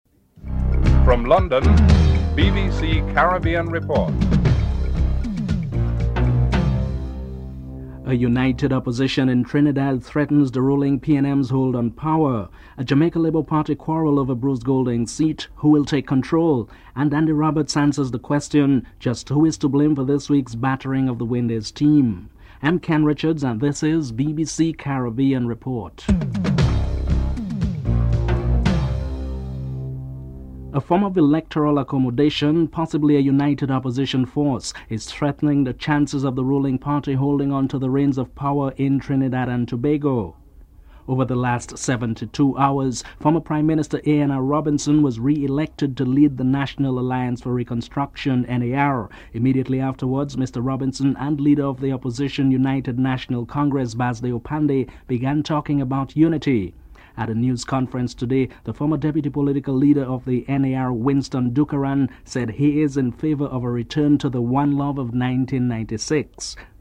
The British Broadcasting Corporation
7. The West Indies manager Andy Roberts answers the question just who is to blame for the battering of the Windies cricket team (12:33-14:50)